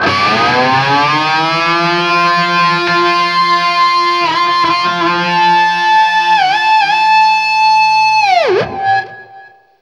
DIVEBOMB 9-L.wav